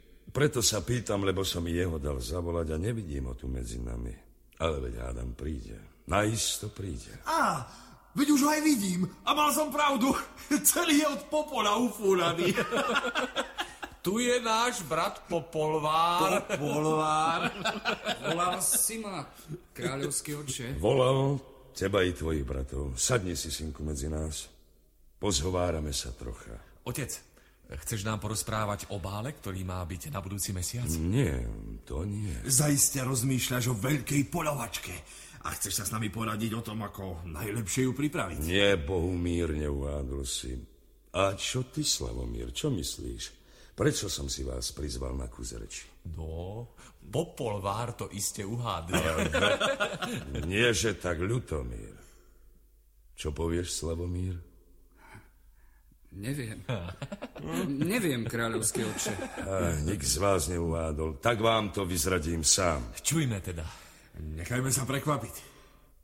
Najkrajšie rozprávky 11 audiokniha
Ukázka z knihy